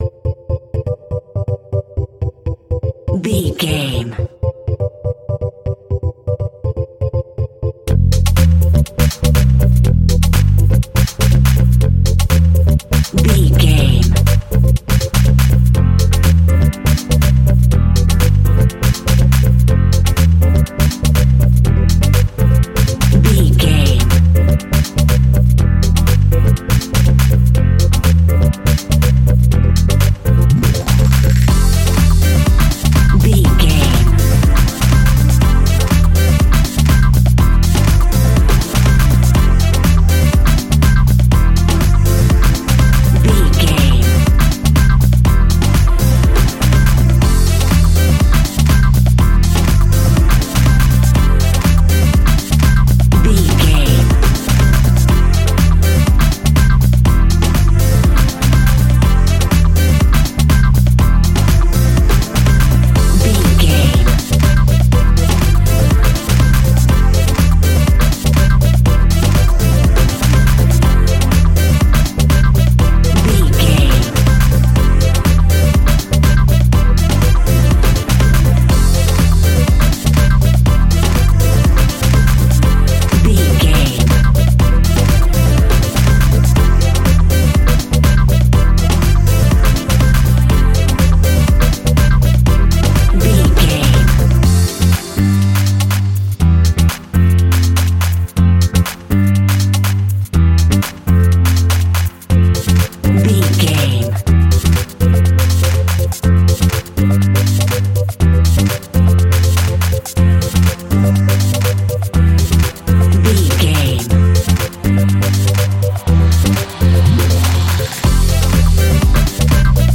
Ionian/Major
groovy
uplifting
bouncy
electric piano
bass guitar
horns
drums
electric guitar
disco house
electro funk
synths
upbeat
clavinet